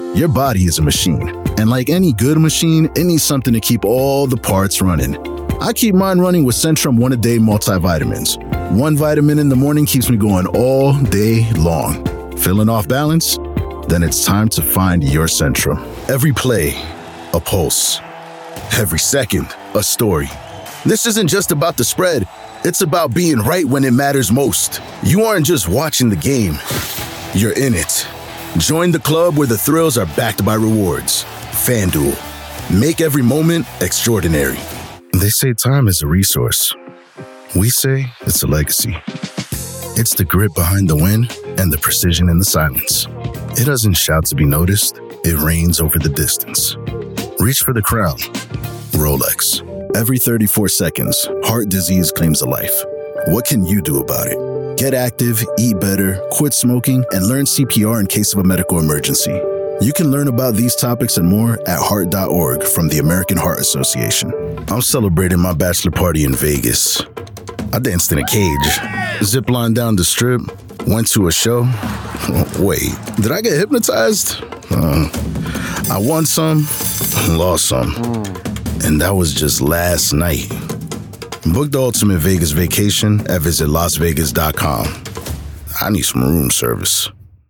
Commercial
• Conditioned room with an assortment of bass traps
• Noise floor of -60dB